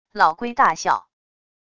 老龟大笑wav音频